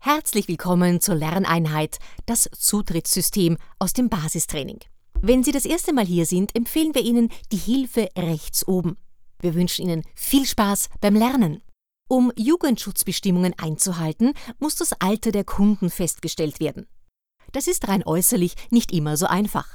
Synchronstimme, bekannt aus TV-und Radio, Moderatorin, Off-Sprecherin,Schauspielerin
Sprechprobe: eLearning (Muttersprache):